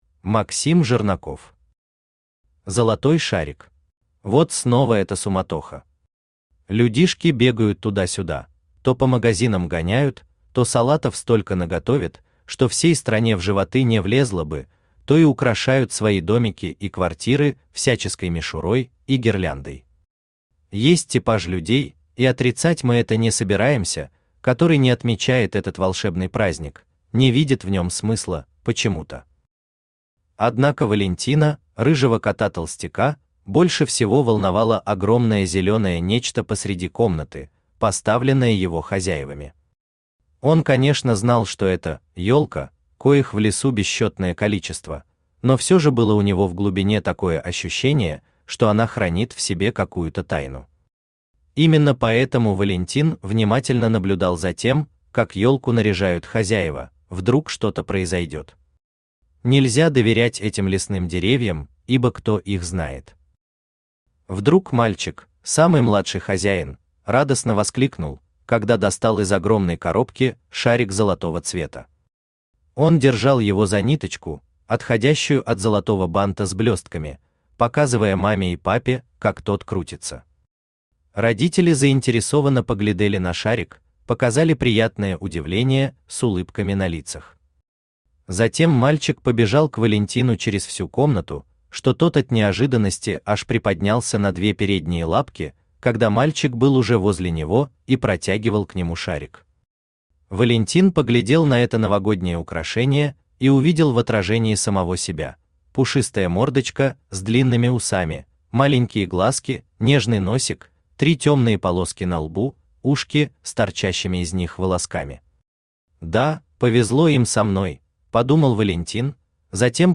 Аудиокнига Золотой Шарик | Библиотека аудиокниг
Читает аудиокнигу Авточтец ЛитРес.